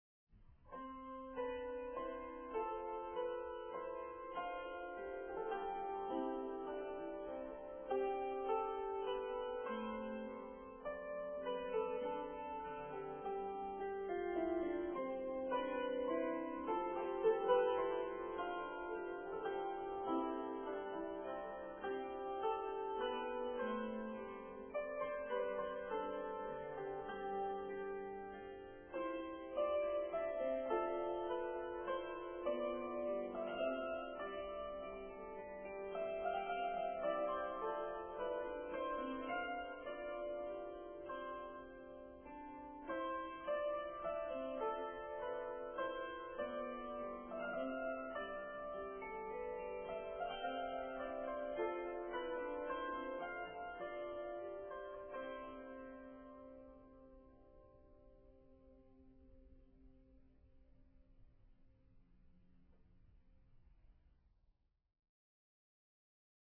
mp3 mono 8kbps